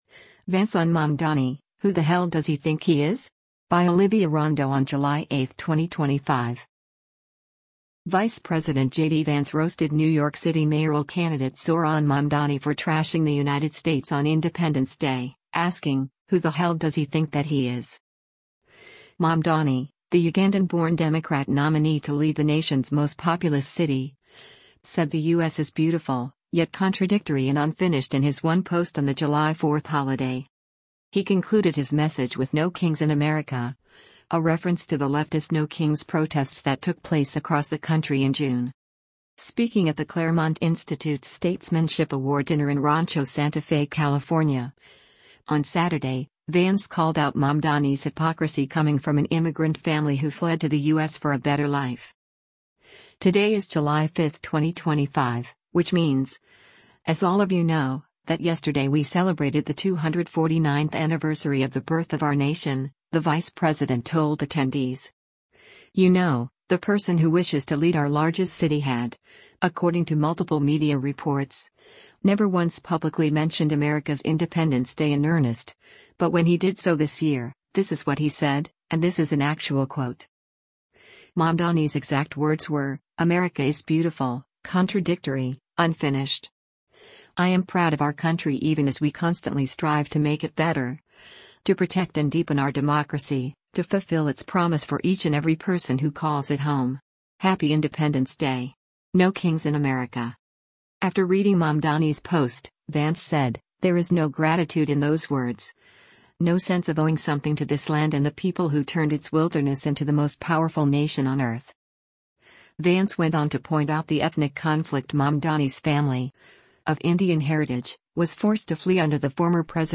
Speaking at the Claremont Institute’s Statesmanship Award dinner in Rancho Santa Fe, California, on Saturday, Vance called out Mamdani’s hypocrisy coming from an immigrant family who fled to the U.S. for a better life:
“Who the hell does he think that he is?” Vance asked, before applause erupted from the audience.